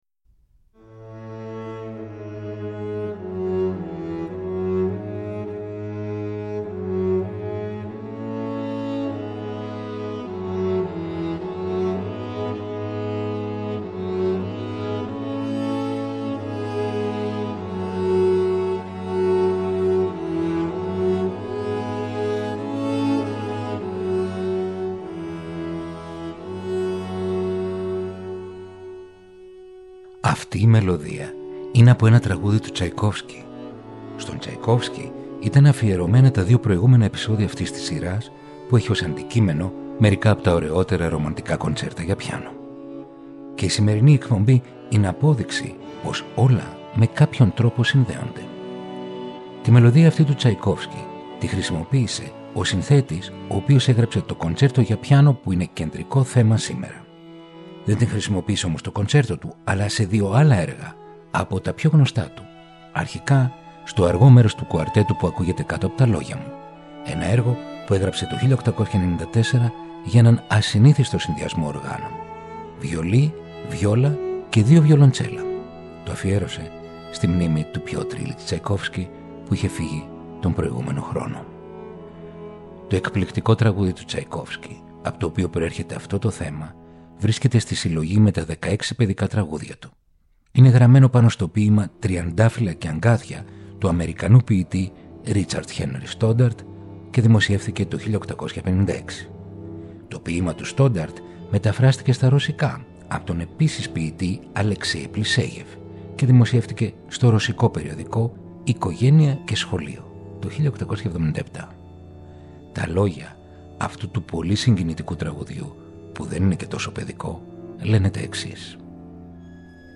Ρομαντικά κοντσέρτα για πιάνο – Επεισόδιο 7ο